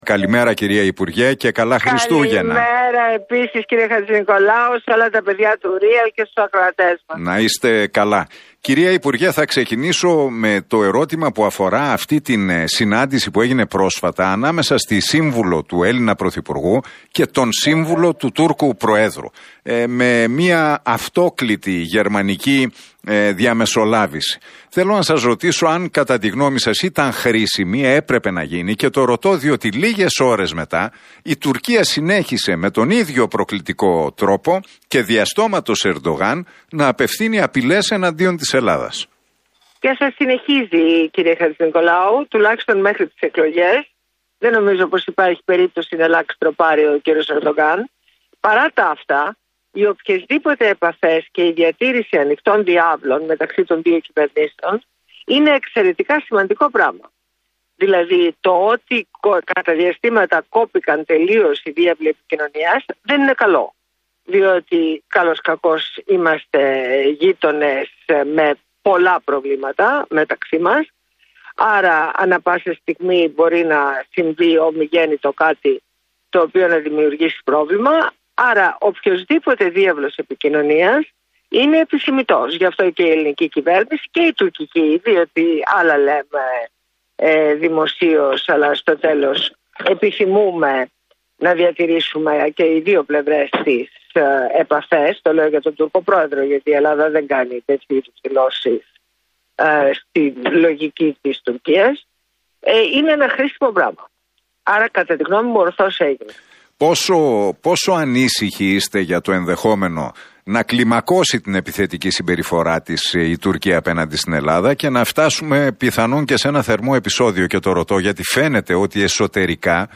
Η Ντόρα Μπακογιάννη παραχώρησε συνέντευξη στον Realfm 97,8 και στην εκπομπή του Νίκου Χατζηνικολάου.